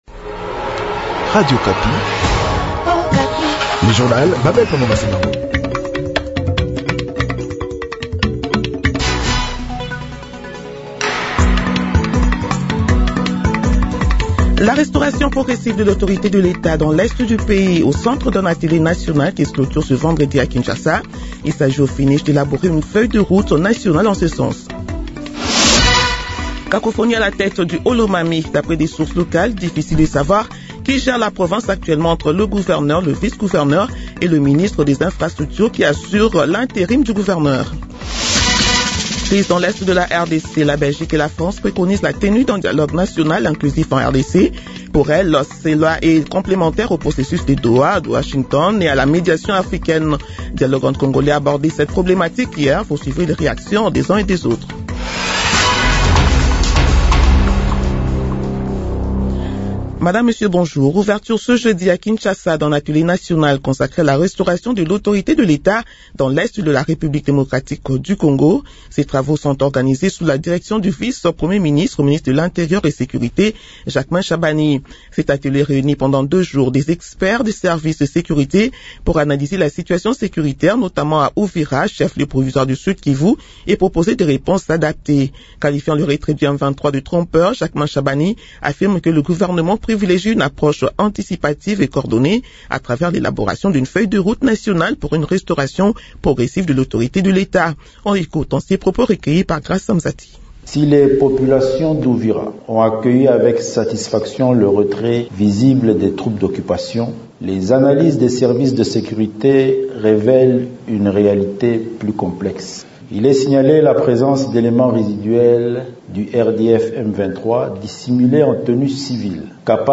Journal 6 heures de ce vendredi 23 janvier 2026